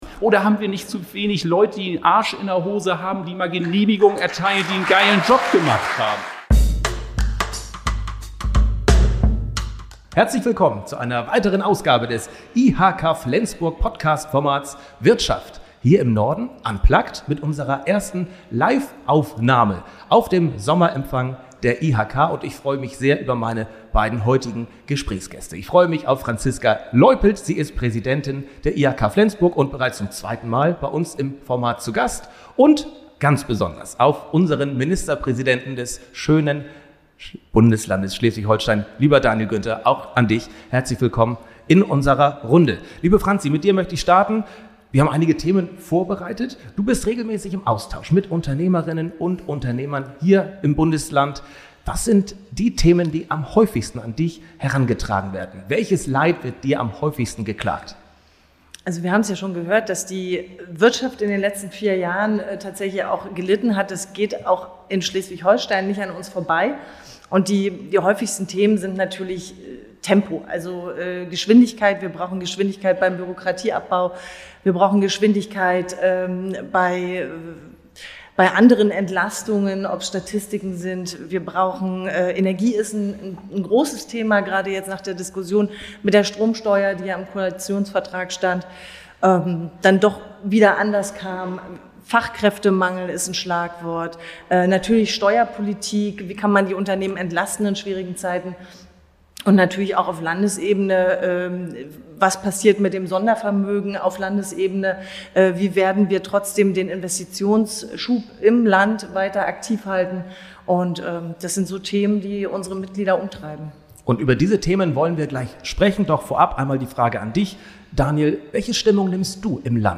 #5: Daniel Günther live auf dem IHK-Sommerempfang